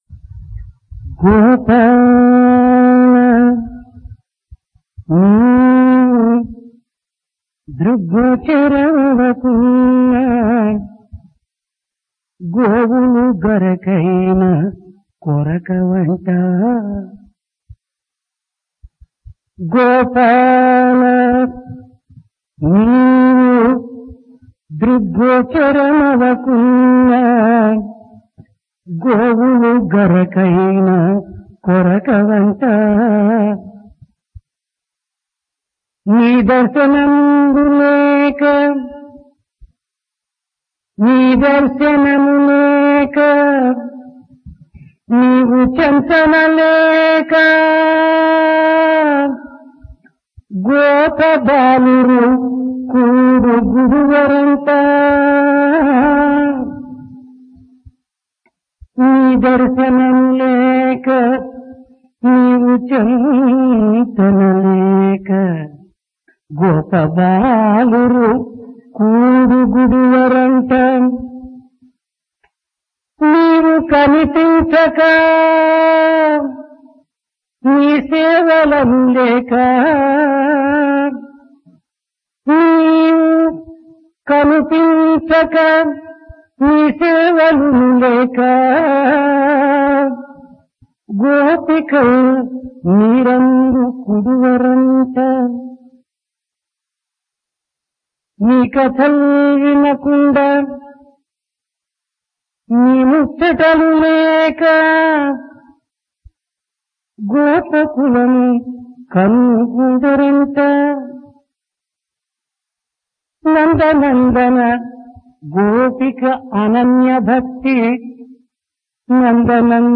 Dasara - Divine Discourse | Sri Sathya Sai Speaks
Divine Discourse of Bhagawan Sri Sathya Sai Baba
Occasion: Dasara, Vijayadasami
Place Prasanthi Nilayam